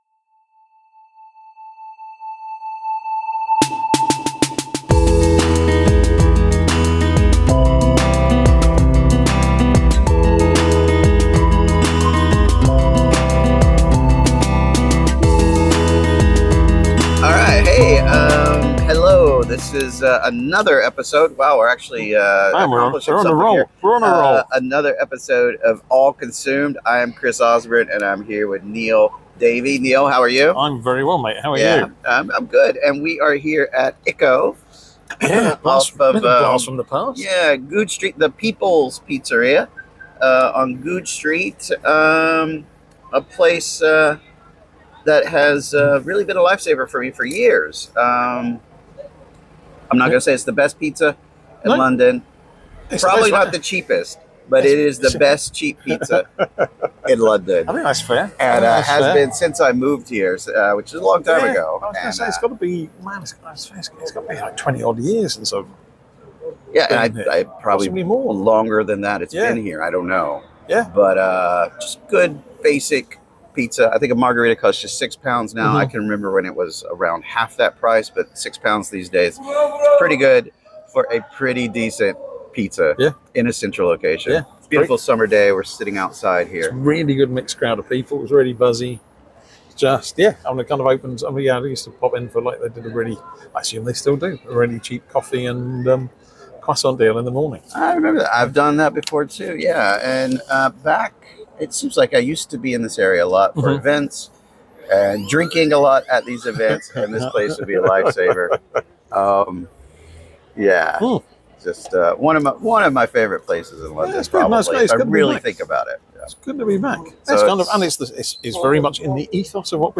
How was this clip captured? catch up at ICCO, the People’s Pizzeria, on Goodge Street.